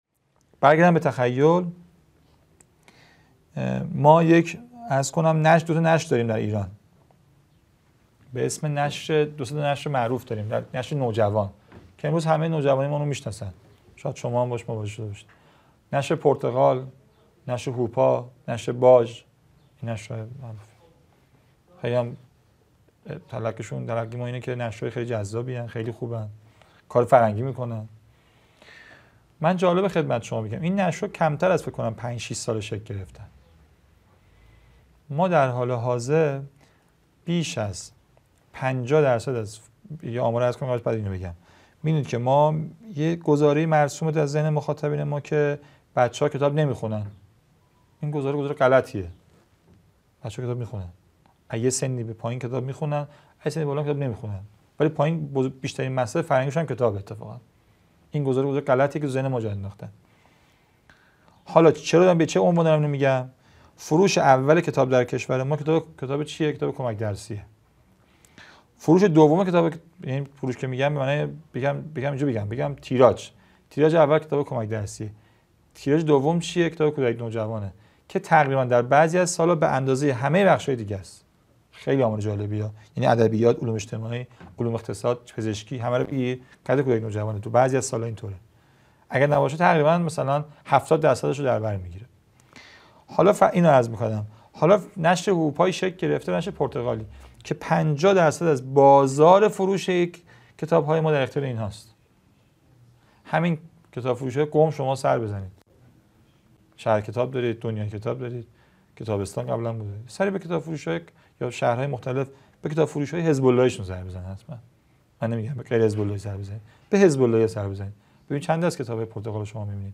قم - اردبیهشت ماه 1402